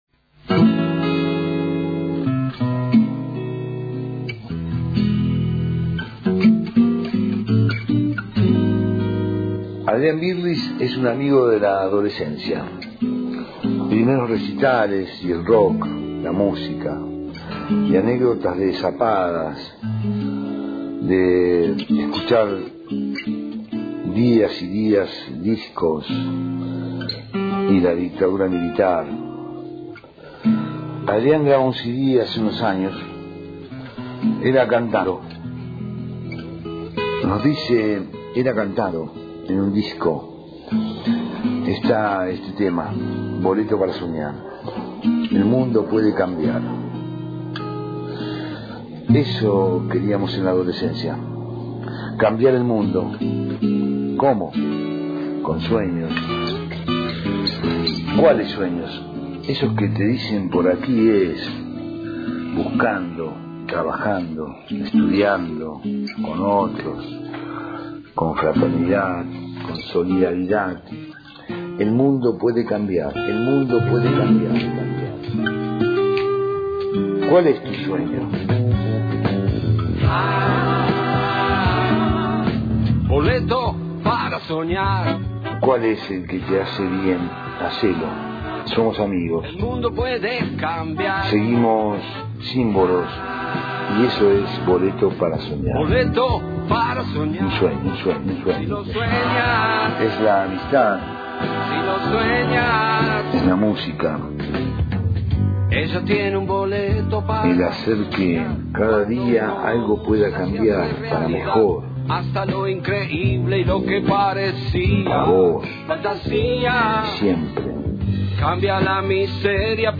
Boleto para Soñar: Charla, música y anécdotas acompañan la lectura